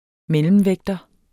Udtale [ ˈmεləmˌvεgdʌ ]